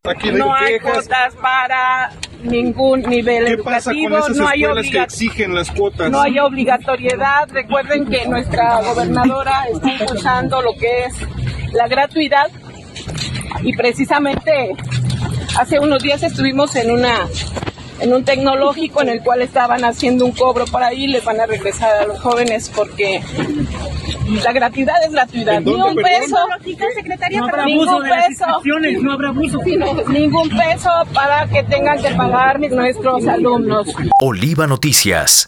En breve entrevista, explicó que la medida aplica para todos los niveles educativos; fue impulsada por la gobernadora, Rocío Nahle García.